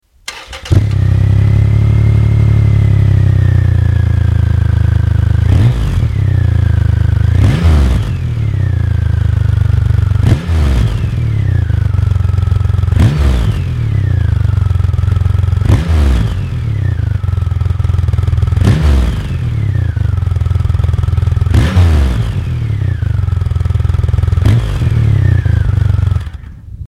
Tyvärr finns bara ett töntigt tomgångsljud på denna best!